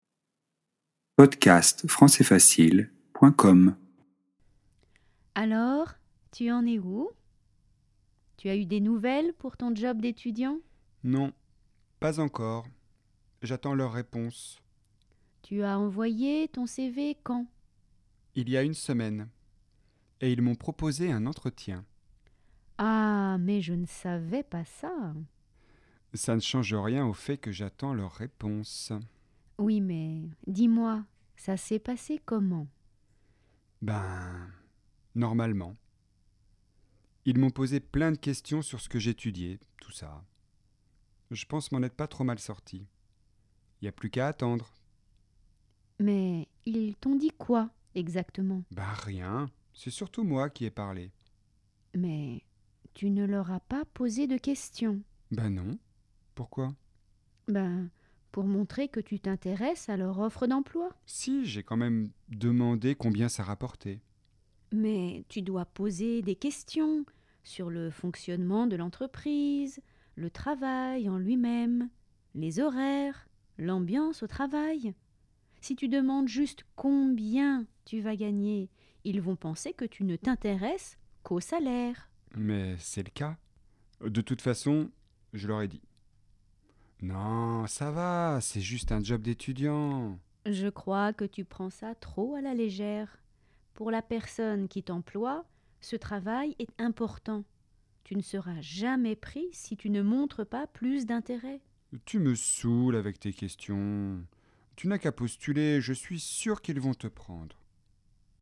🔷 DIALOGUE :